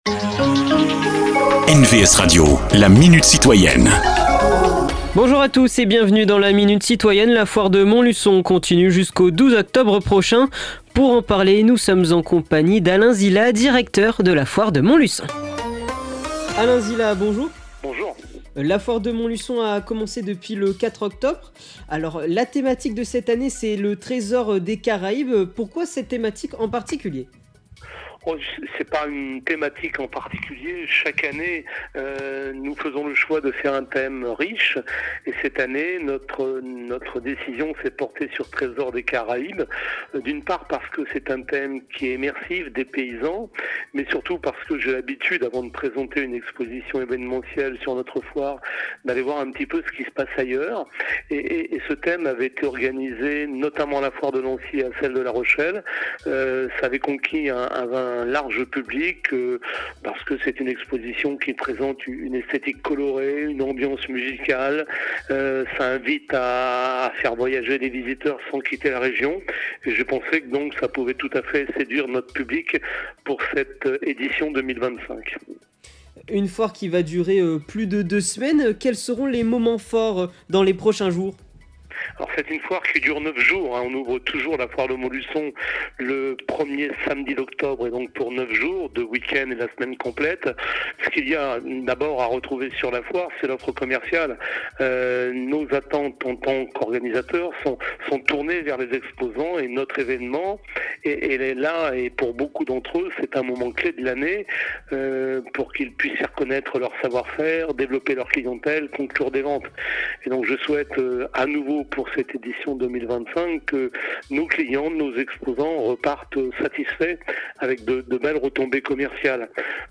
Ils sont élus, maires, sénateurs, députés ou tout simplement citoyen investit dans leurs communes… Rencontre avec ceux qui font l’actualité du territoire.